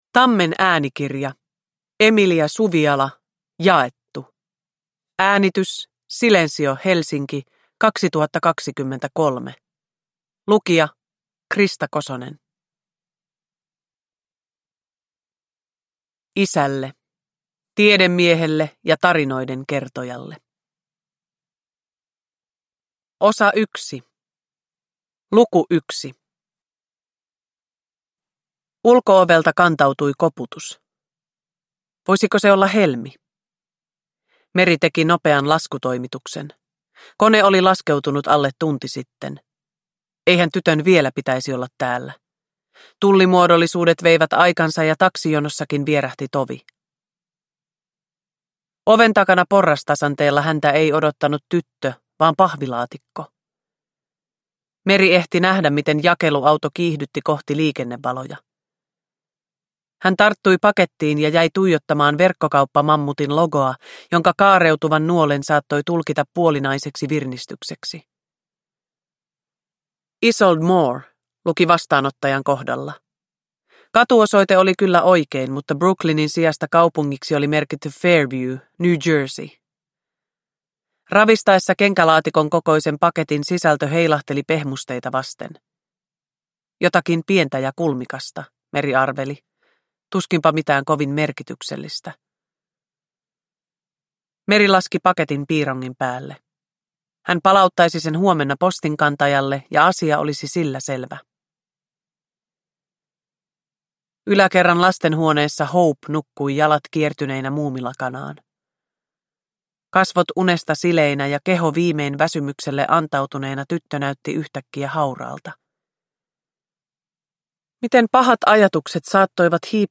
Jaettu – Ljudbok – Laddas ner
Uppläsare: Krista Kosonen